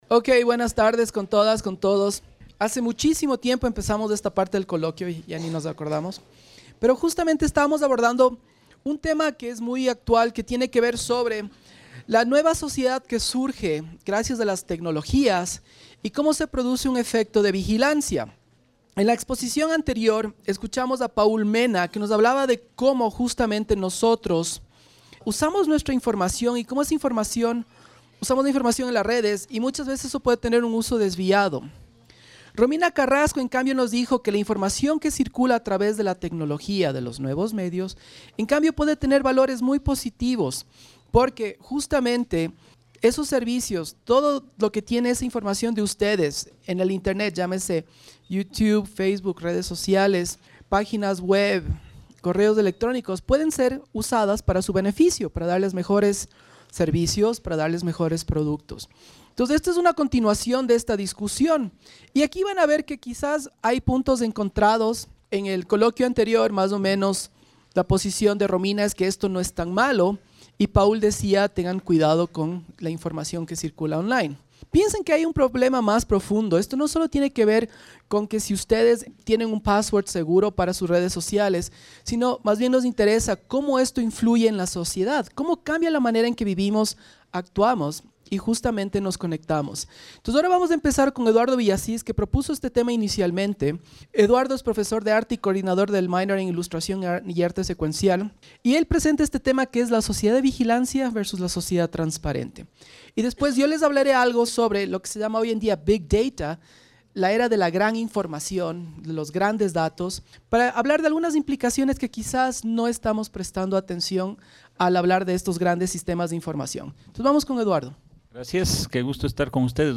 coloquios